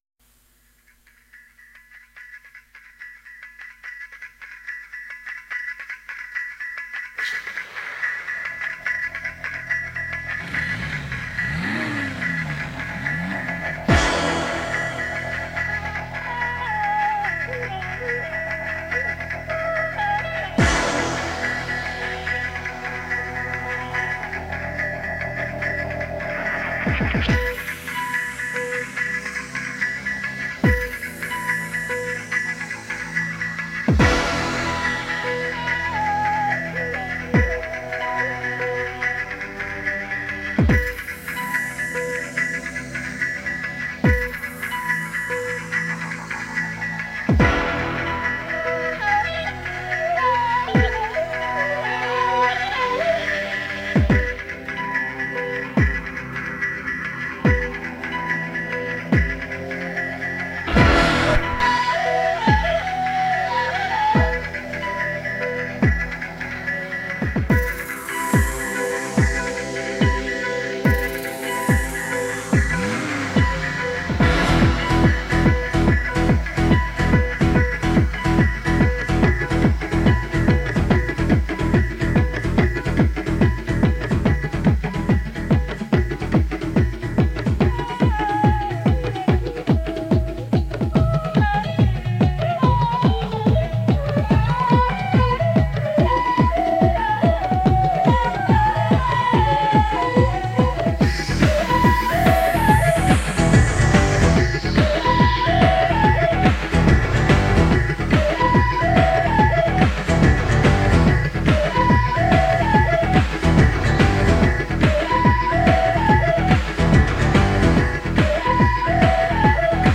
Cette version est remasterisée par l'IA de Suno.
• Format : ogg (stéréo)